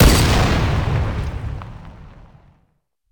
plasrifle.ogg